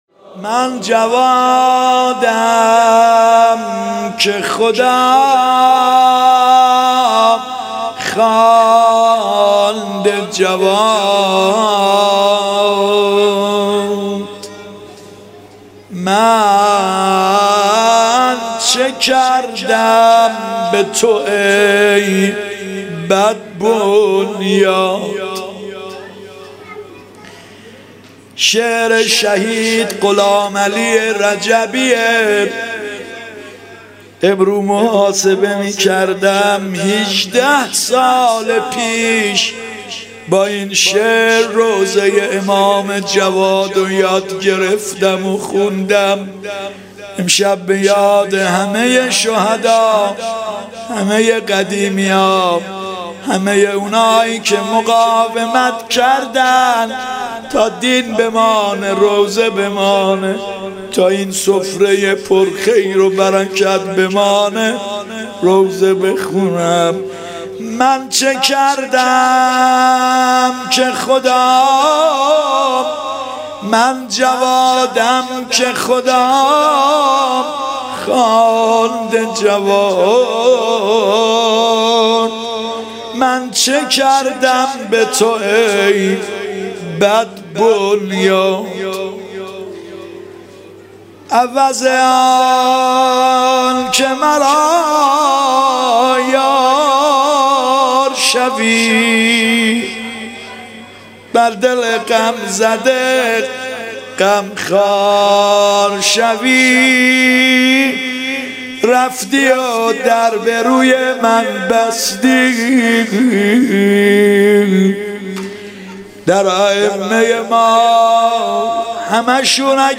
شهادت امام جواد (ع) 97-96